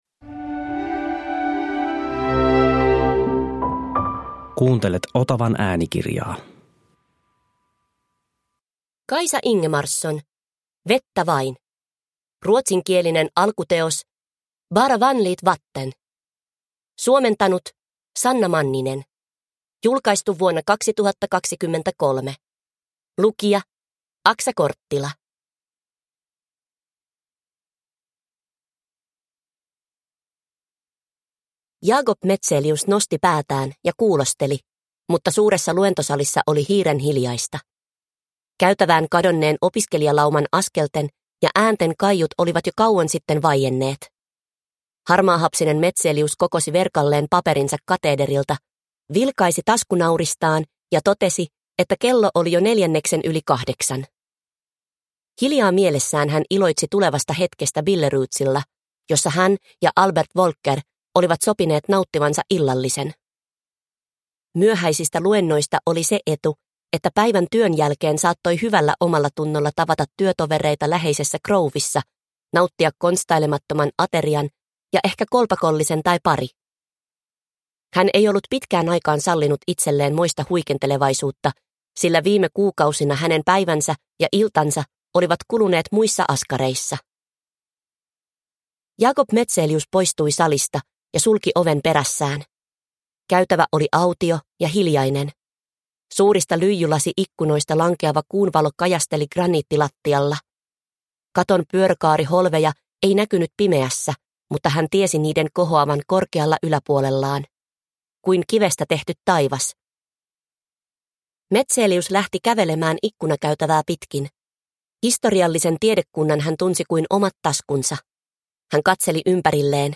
Vettä vain – Ljudbok – Laddas ner